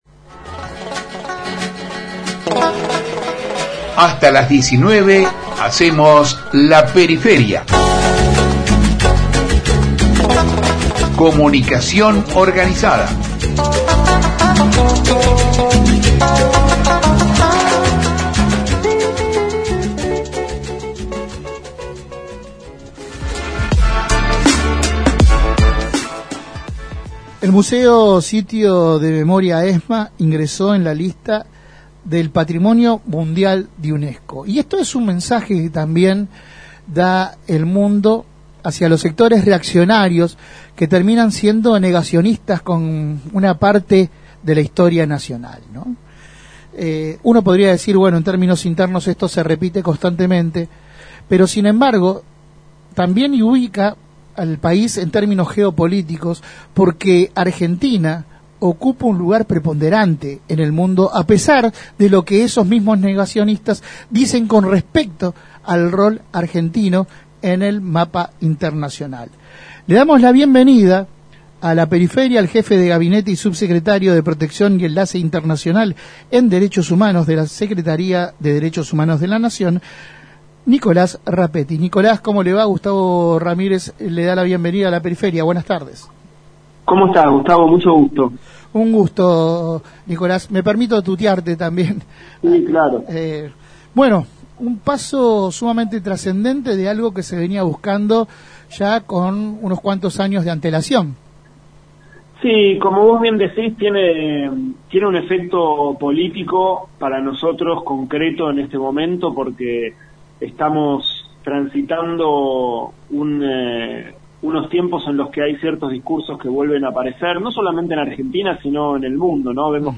En La Periferia dialogamos con el Jefe de Gabinete y Subsecretario de Protección y Enlace Internacional de la Secretaría de Derechos Humanos de la Nación, Nicolás Rapetti. El funcionario destacó la importancia de la resolución de la UNESCO.
Compartimos la entrevista completa: